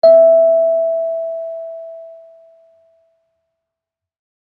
kalimba1_circleskin-E4-ff.wav